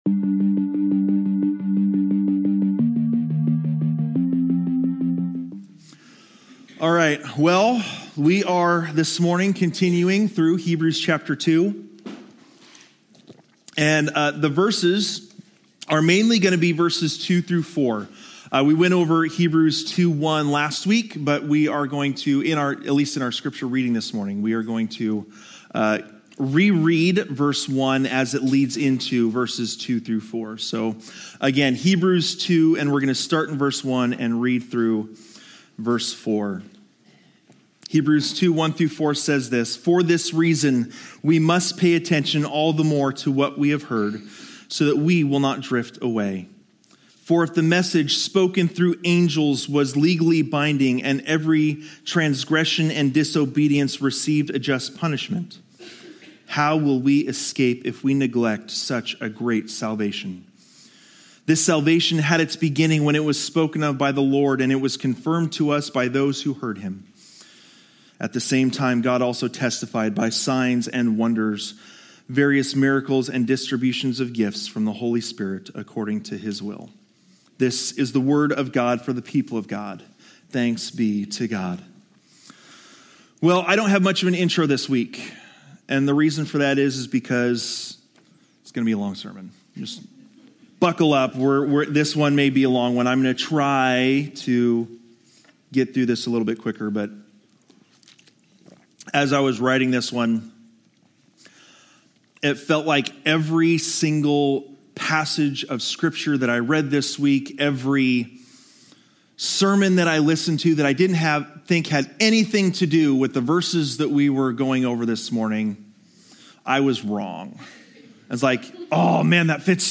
This sermon challenges us to confront our spiritual state and to pursue a deeper relationship with God.